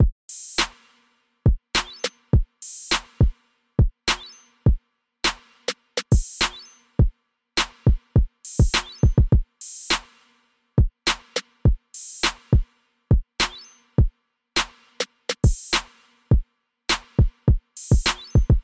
油腻的陷阱大鼓
描述：恶心的Trap节拍，与油腻的808和油腻的钢琴循环相配。
Tag: 103 bpm Trap Loops Drum Loops 3.14 MB wav Key : Unknown